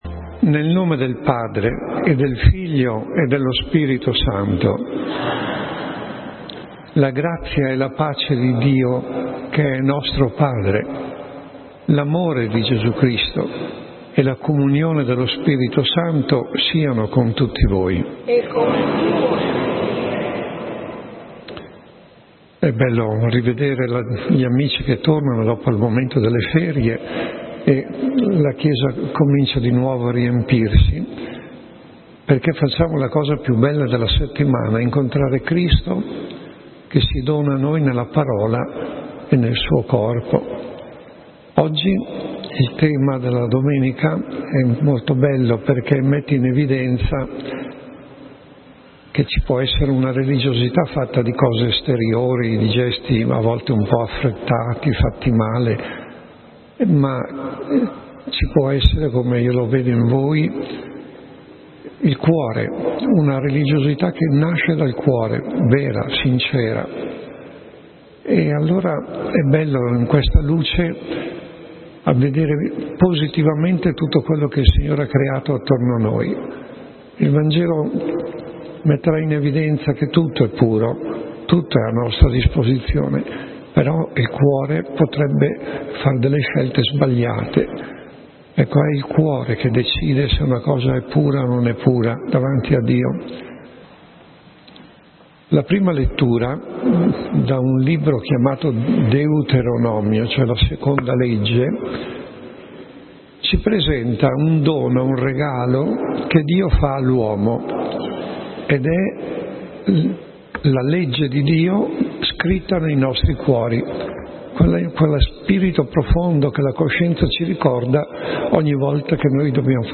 Audio torna all'elenco Omelia della XXII Domenica del tempo ordinario B - 02_09_2018 Omelia della XXII Domenica del tempo ordinario B - 02_09_2018 Scarica il file audio: 44.